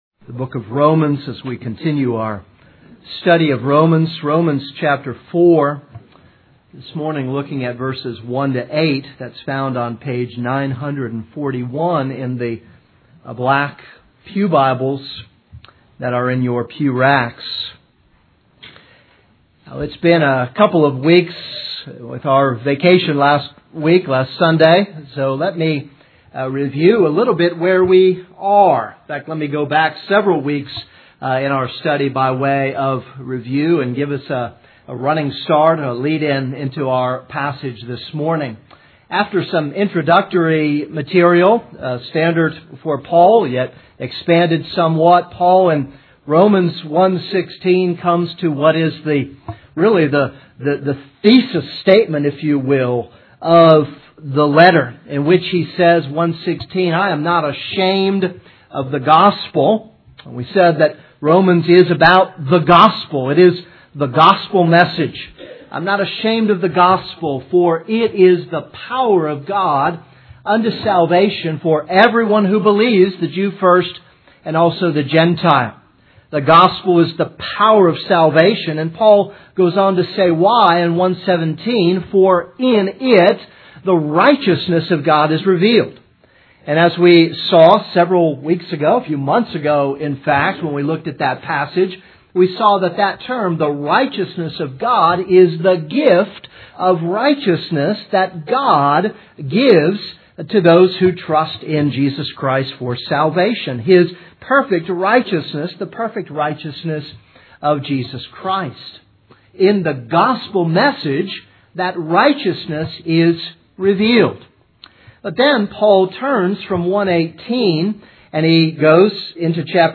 This is a sermon on Romans 4:1-8.